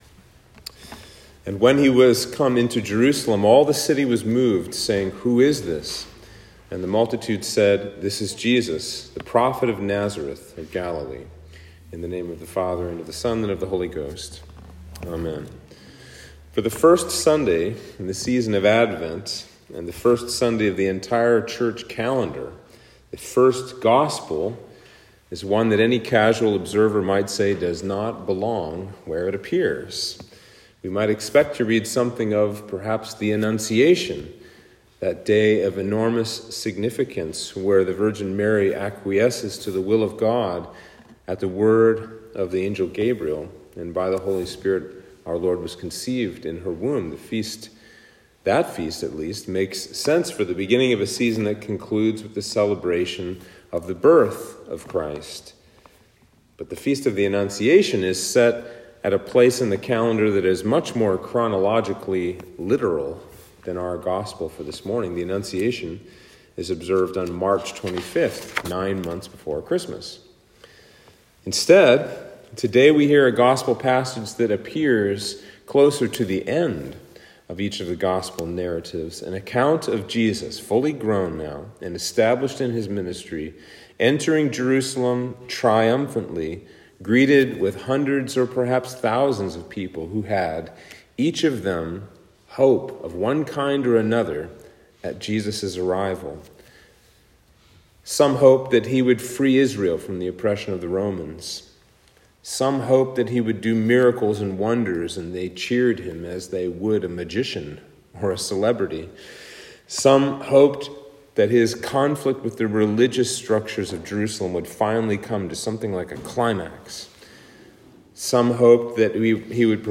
Sermon for Advent 1